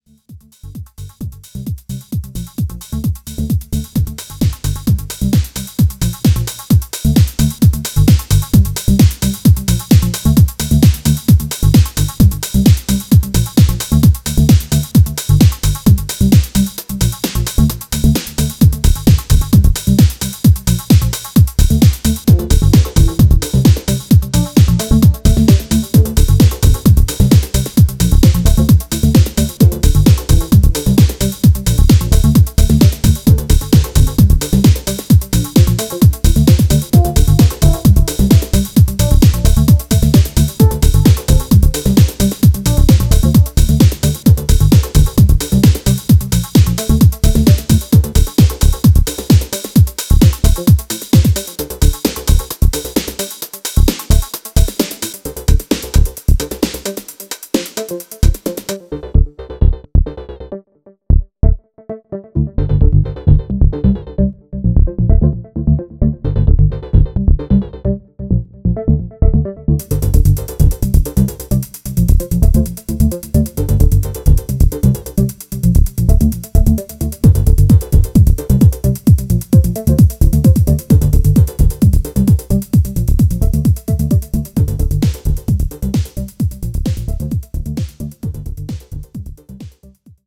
sultry sounds